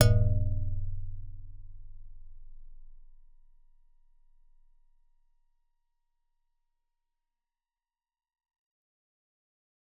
G_Musicbox-D0-f.wav